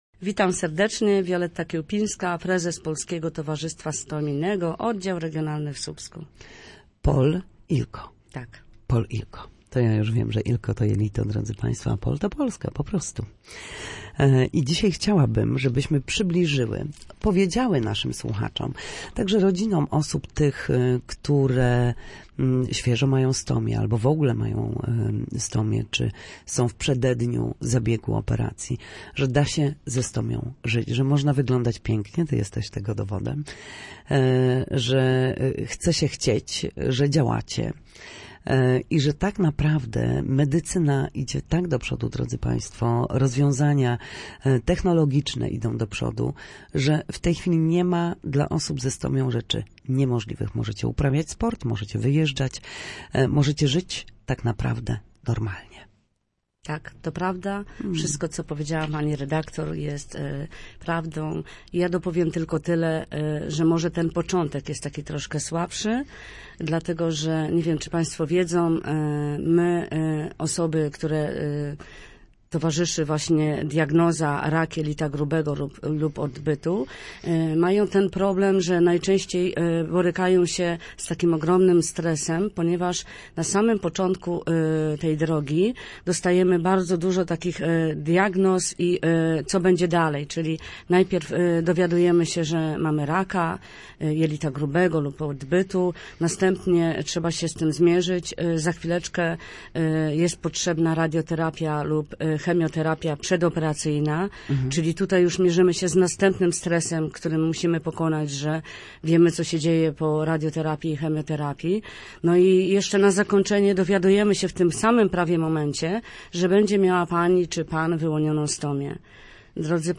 W każdą środę w popołudniowym paśmie Studia Słupsk Radia Gdańsk dyskutujemy o tym, jak wrócić do formy po chorobach i urazach. W audycji „Na zdrowie” lekarze i fizjoterapeuci odpowiadają na pytania dotyczące najczęstszych dolegliwości, podpowiadają, jak wyleczyć się w domowych warunkach, i zachęcają do udziału w nowych projektach.